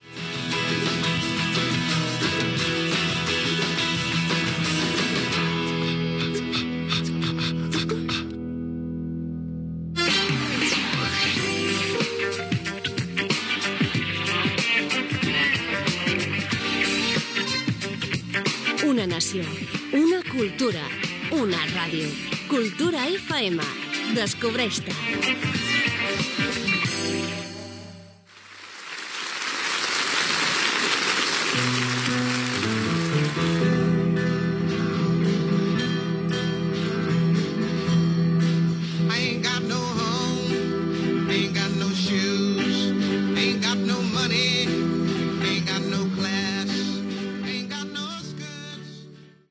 Tema musical, indicatiu de l'emissora, tema musical